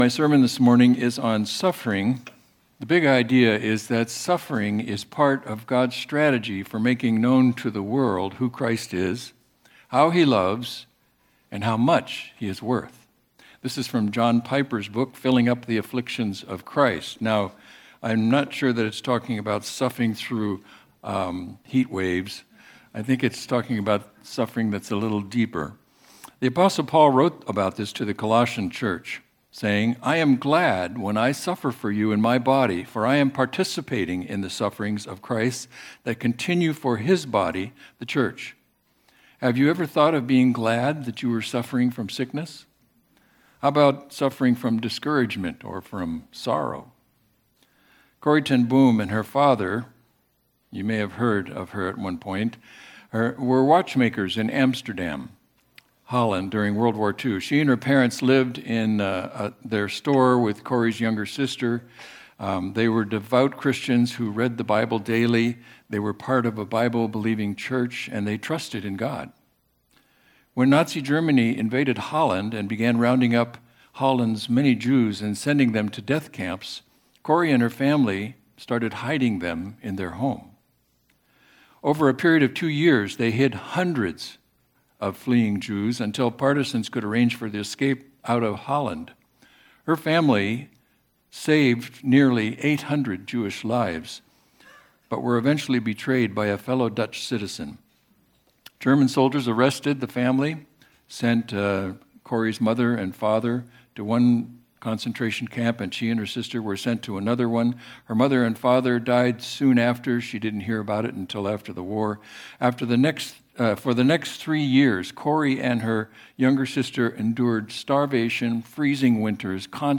Series: Special Sermon